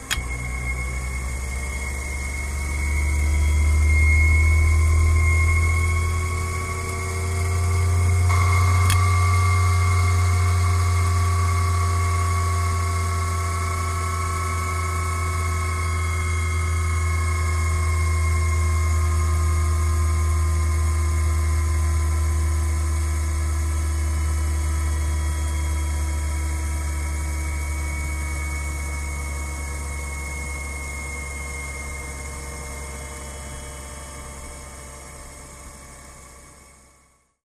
Machine, Wind Up / Down; Large Centrifuge Clicks On, Winds Up And Down. Left Channel Air Mic, Right Channel Contact Mic